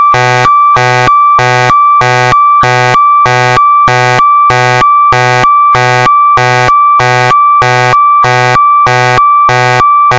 描述：Cartoonlike siren recreated on a Roland System100 vintage modular synth
标签： cartoon scifi siren Synthetic
声道立体声